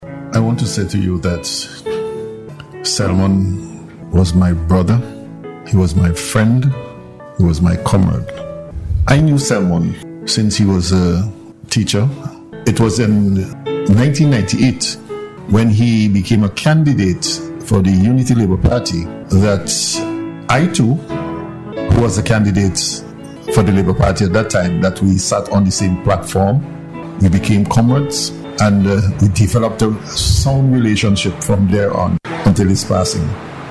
Speaking during this morning’s official ceremony held at the House of Assembly Chamber, Minister Daniel said he knew Walters as a teacher before his life in active politics and they maintained a great bond until the very end.